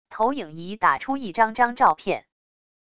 投影仪打出一张张照片wav音频